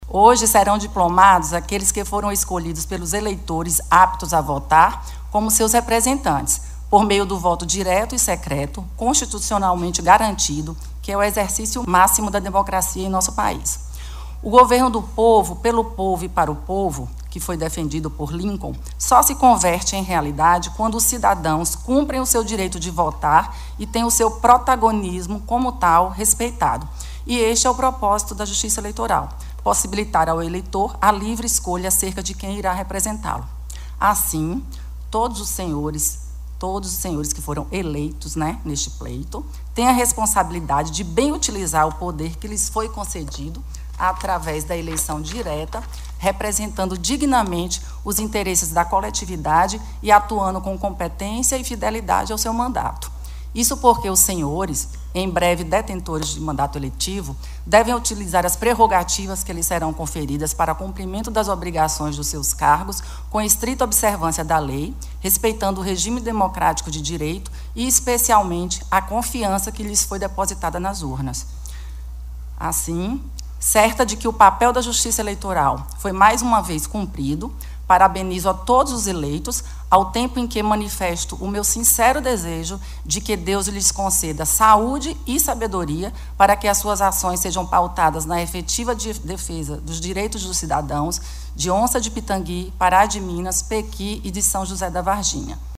A diplomação dos eleitos ocorreu na noite desta sexta-feira (13), na Câmara Municipal de Pará de Minas, em solenidade presidida pela juíza Gabriela Andrade de Alencar Ramos.
A juíza Gabriela Andrade de Alencar Ramos destacou em seu discurso que a Justiça Eleitoral atuou de forma eficiente para garantir toda a tranquilidade no processo aos candidatos e aos eleitores: